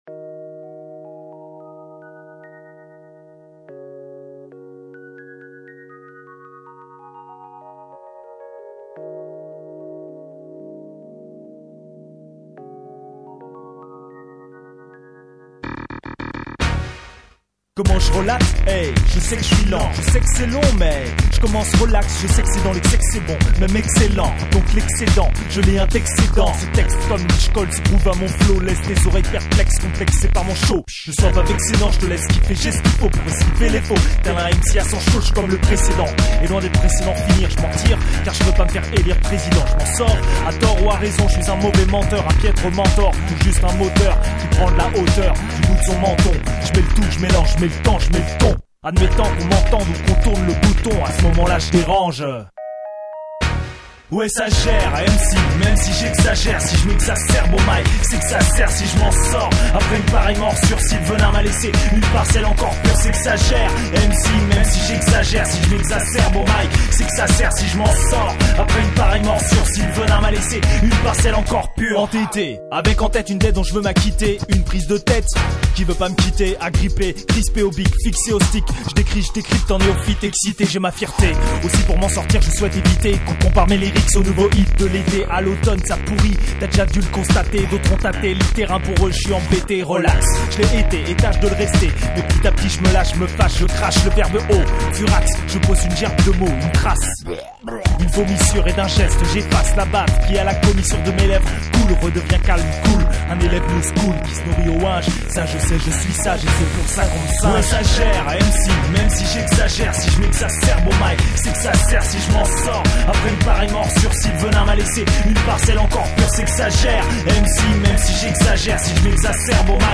rappeur